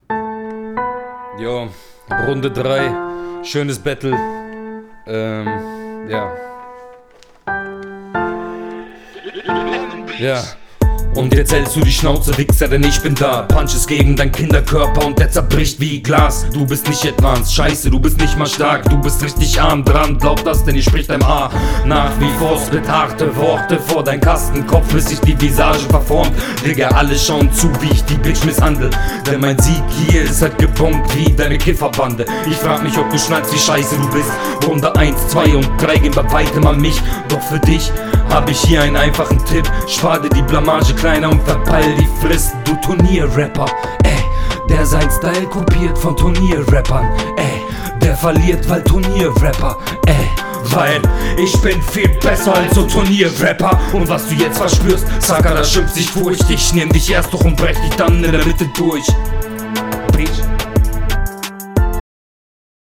Flow und Stimme geil.